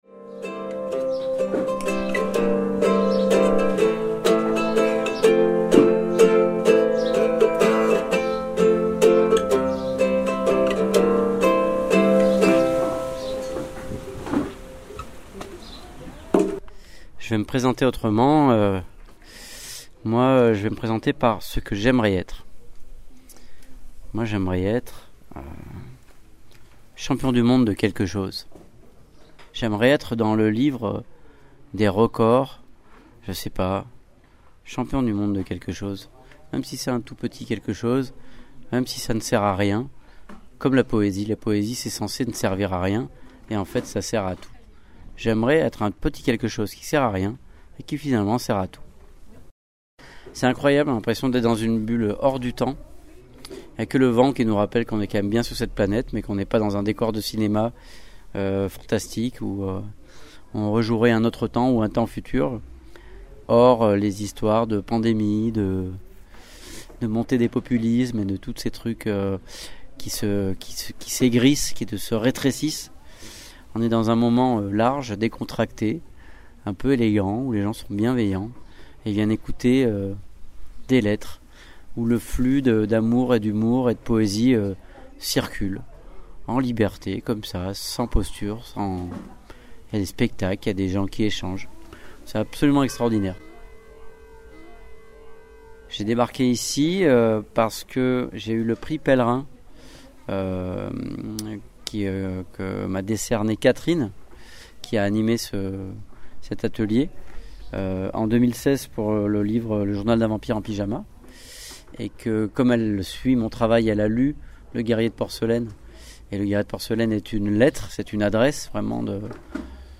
C'est au Festival de la correspondance de Grignan que nous avons rencontré Mathias Malzieu ... au cours d'un atelier d'écriture: lettres d'amour, lettres d'humour